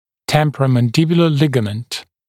[ˌtempərəmən’dɪbjulə ‘lɪgəmənt]